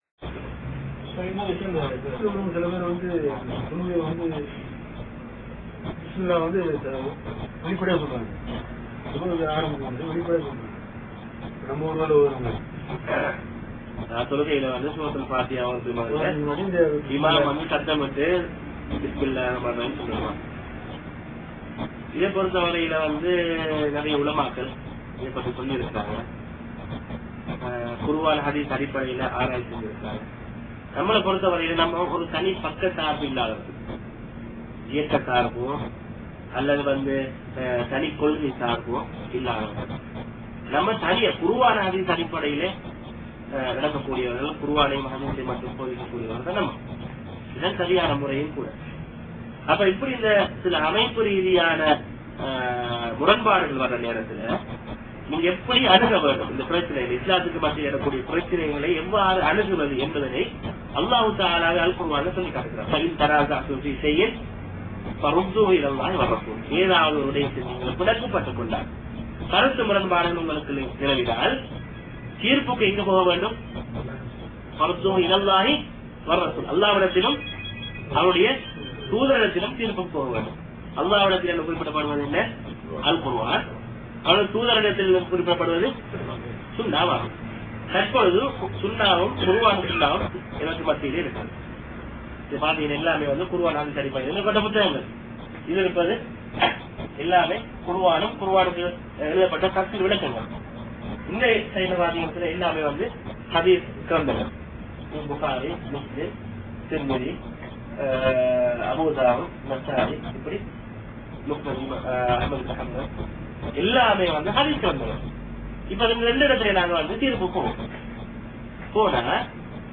வாராந்திர பயான் நிகழ்ச்சி (கேள்வி-பதில் பகுதி)
இடம் : அல்-கப்ஜி, சவூதி அரேபியா